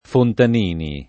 [ fontan & ni ]